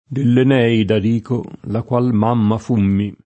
de ll en$-ida d&ko, la kUal m#mma f2mmi], Dante) o con valore di escl. (es. m. santa!) — solo da mamma l’accr. mammona, il dim. mammina, ecc.